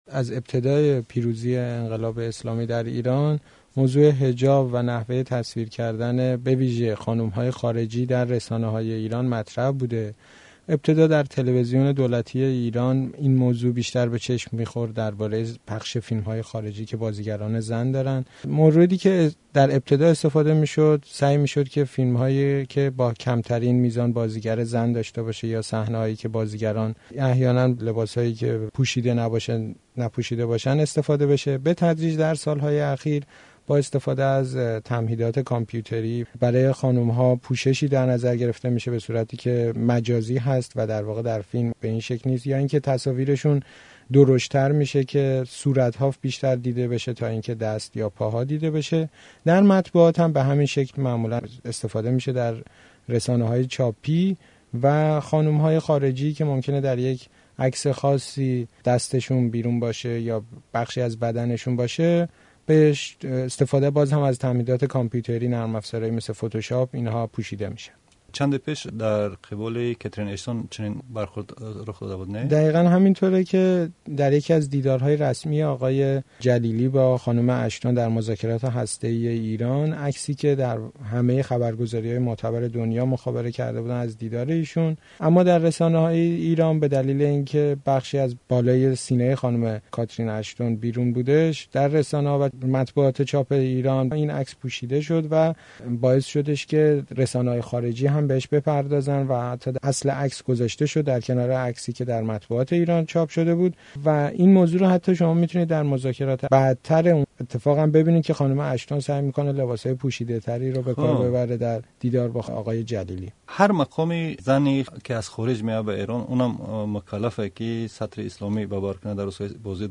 Гуфтугӯ